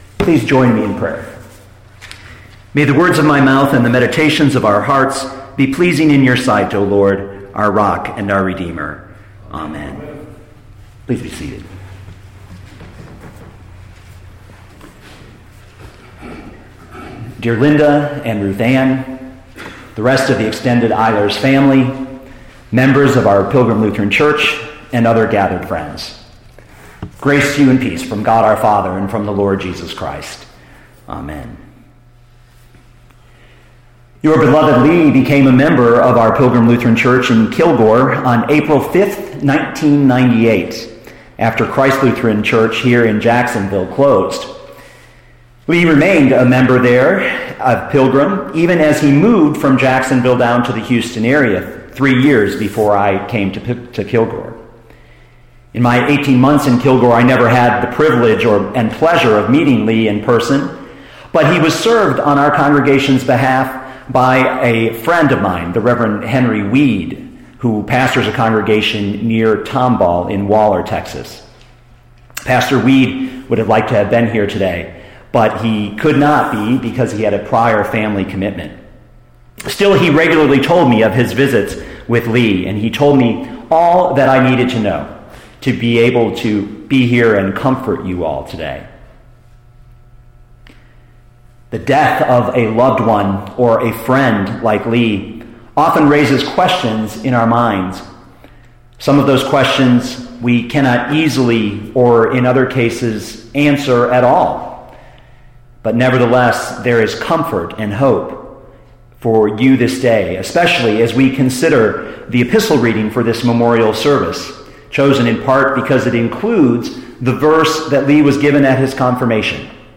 2013 Romans 8:31-39 Listen to the sermon with the player below, or, download the audio.